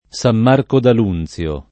Sam m#rko d al2nZLo] (Sic.), San Marco Argentano [Sam m#rko arJent#no] (Cal.), San Marco la Catola [